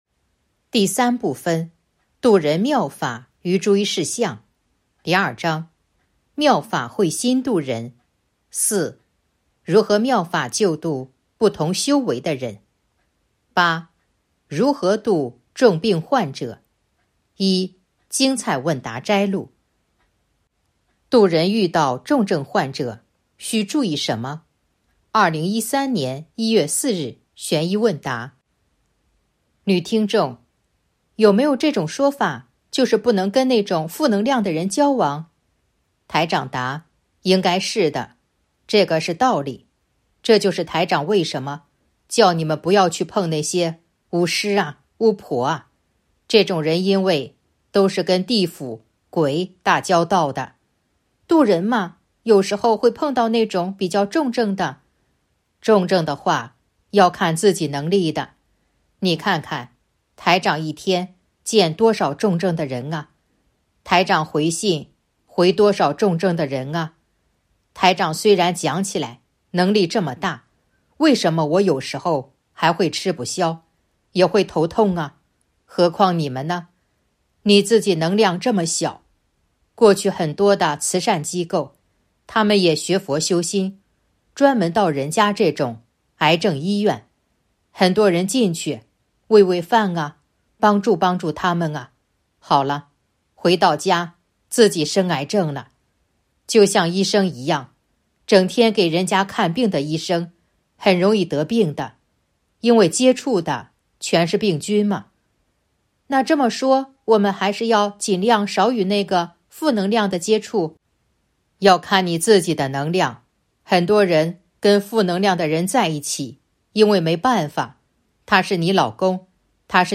035.（八）如何度重病患者 1. 精彩问答摘录《弘法度人手册》【有声书】